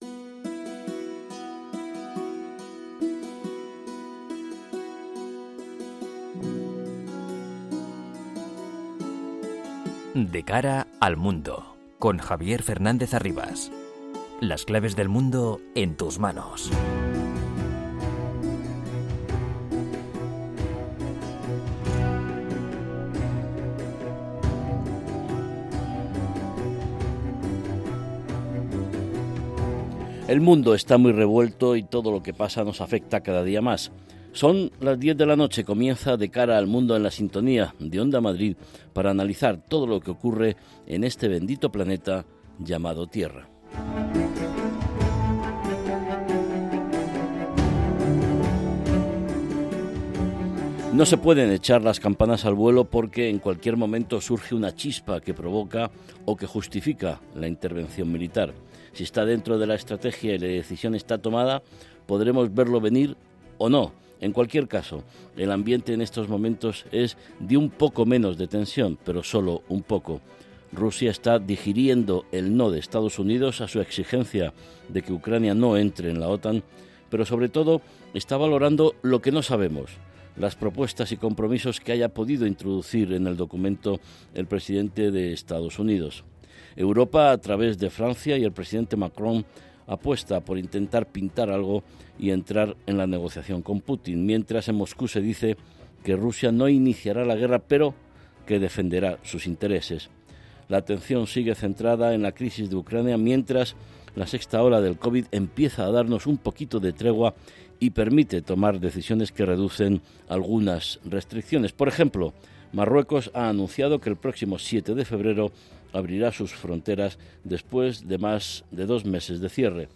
con entrevistas a expertos y un panel completo de analistas